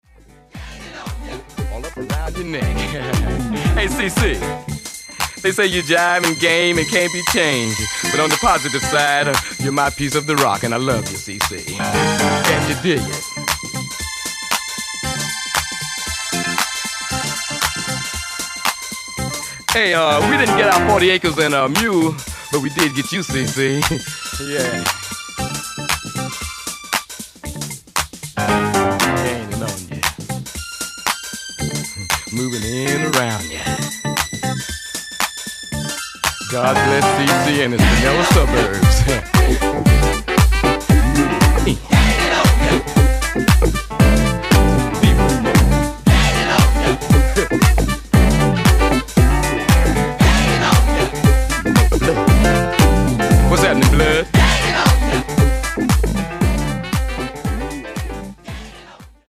supplier of essential dance music
The 4 tracks here are re-edits